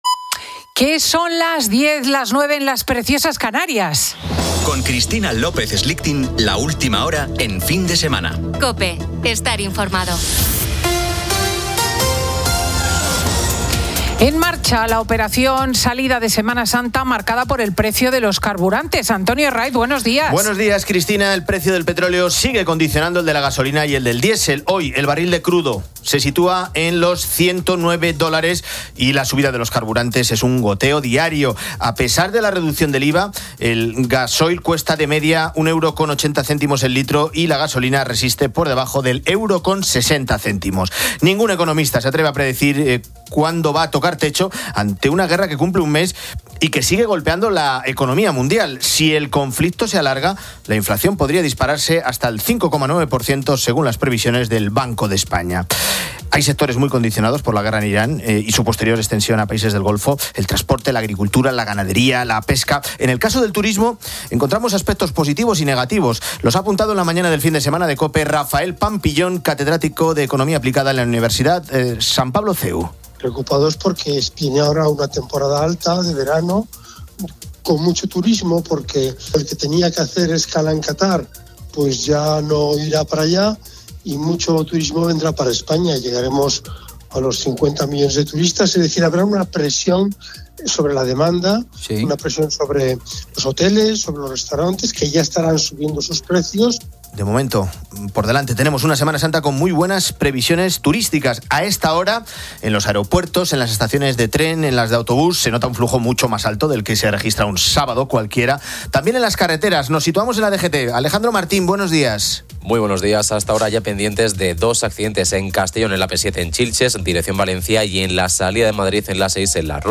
Fin de Semana 10:00H | 28 MAR 2026 | Fin de Semana Editorial de Cristina López Schlichting.